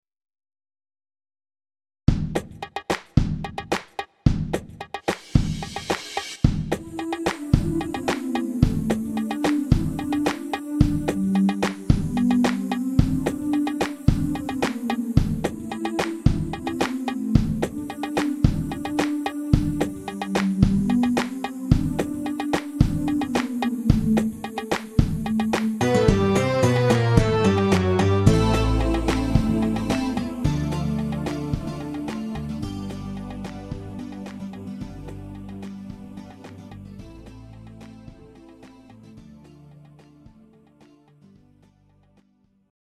Greek Tsifteteli